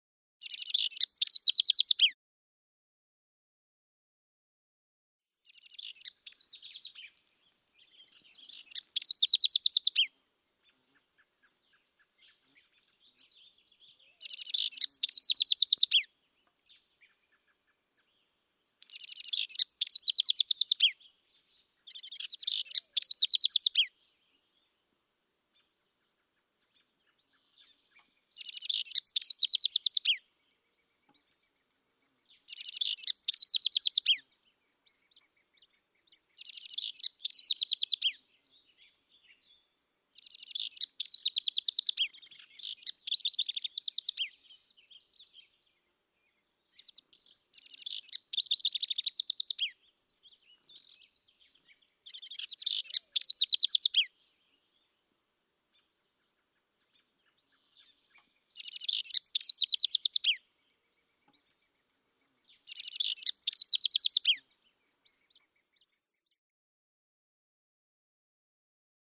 House Wren Single Call and Calling